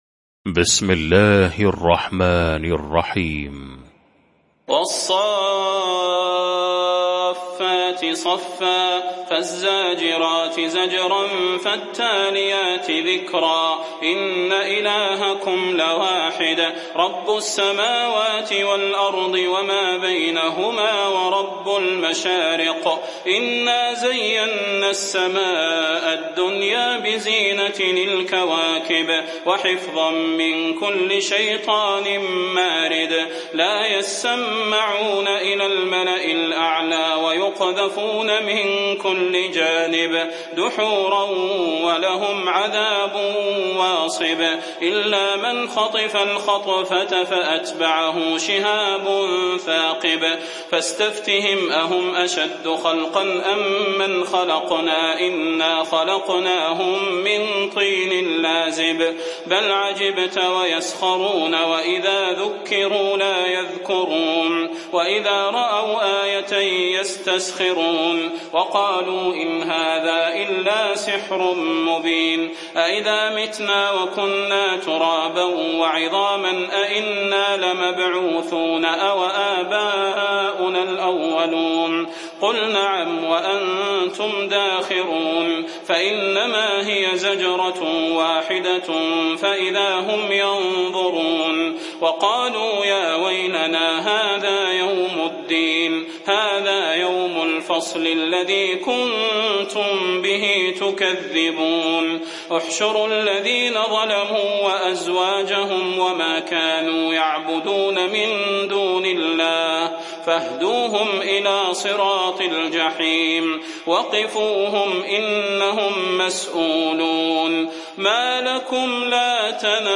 المكان: المسجد النبوي الشيخ: فضيلة الشيخ د. صلاح بن محمد البدير فضيلة الشيخ د. صلاح بن محمد البدير الصافات